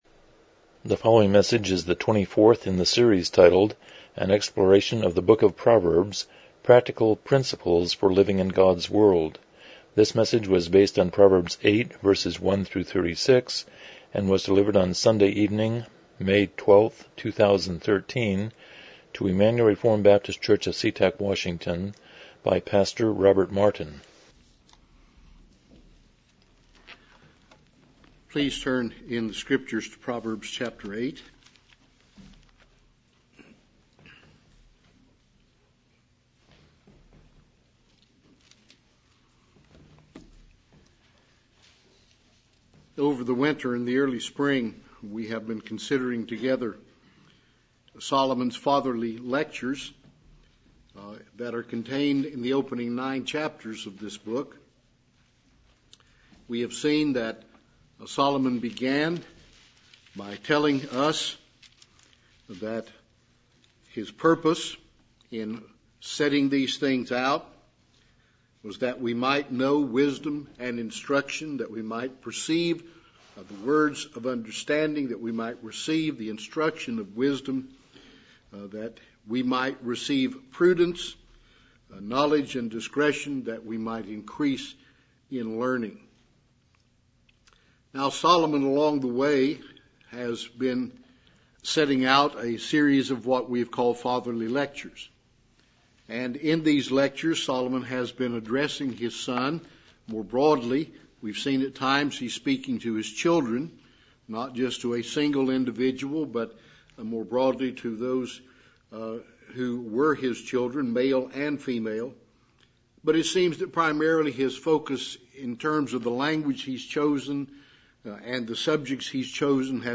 Proverbs 8:1-36 Service Type: Evening Worship « 7 The Charismatic View of Testing Prophets 8 What is the “Burden” of the Lord »